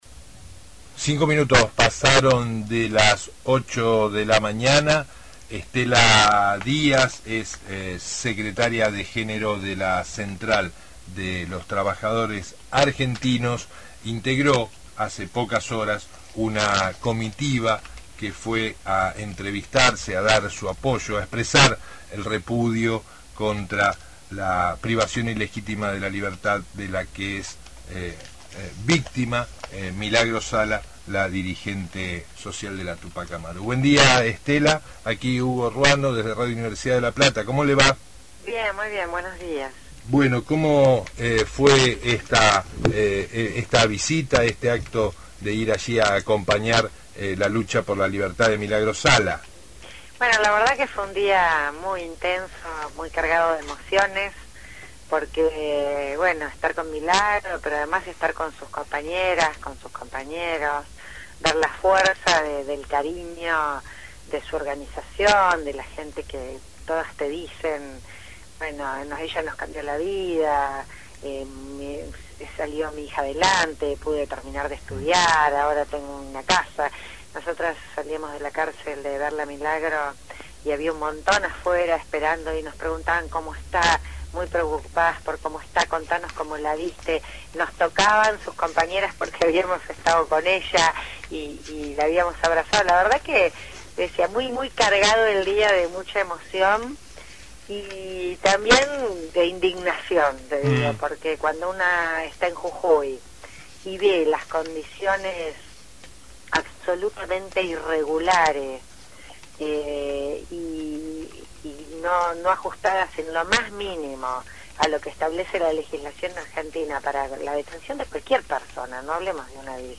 Estela Díaz, secretaria de género de la Central de Trabajadores de la Argentina (CTA)